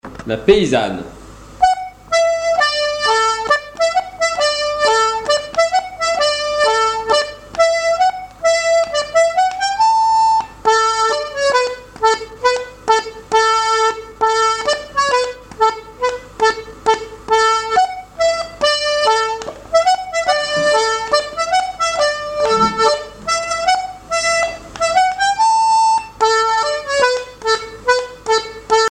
Genre brève
airs de danse à l'accordéon diatonique
Pièce musicale inédite